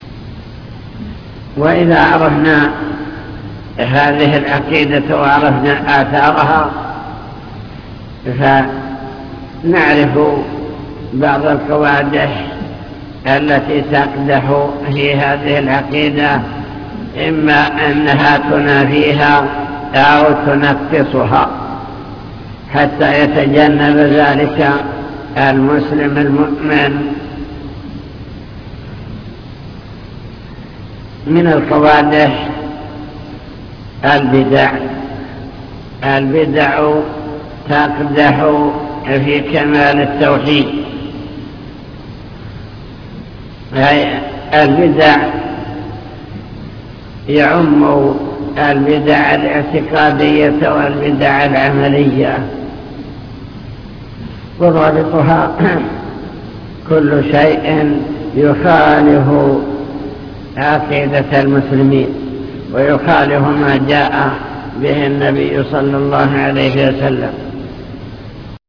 المكتبة الصوتية  تسجيلات - محاضرات ودروس  قوادح في العقيدة